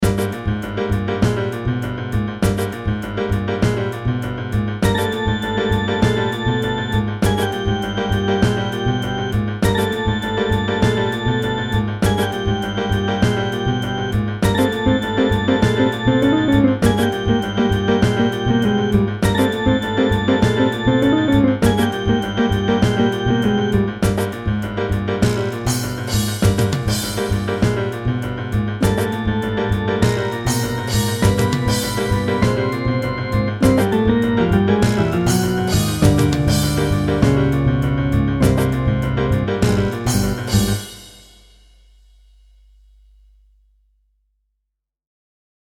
Música jazzy para acción o persecución
jazz